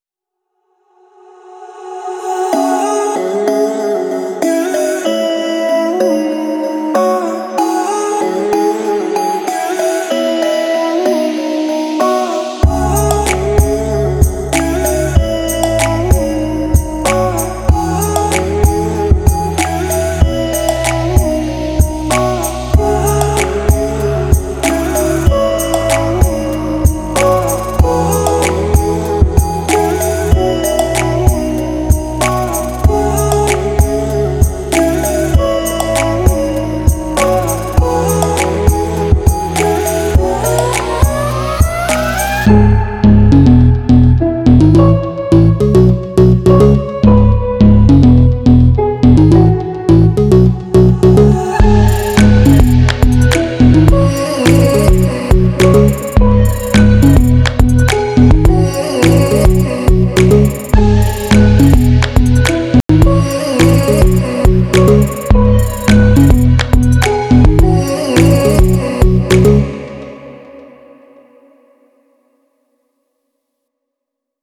此包包含各种元音一声，短语和歌词提取，非常适合切碎和音调。